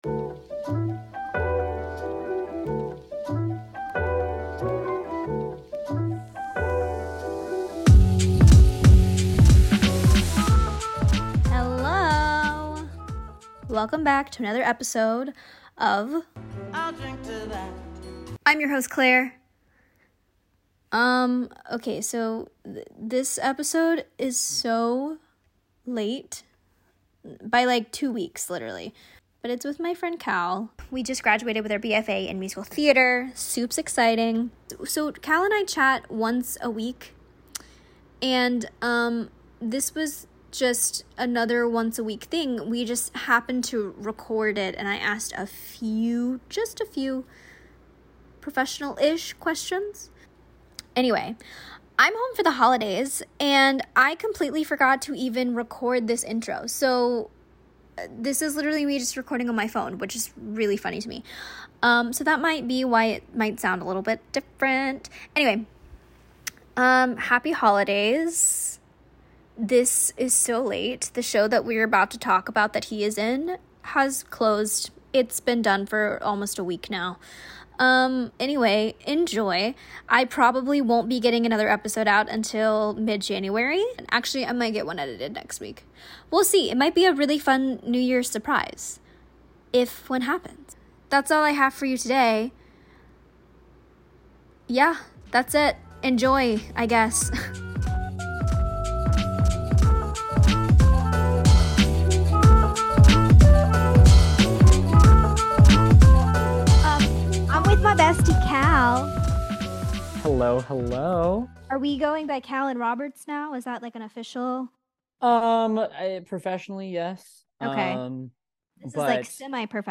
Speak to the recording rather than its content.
🎤 Shure SM58 with Focusrite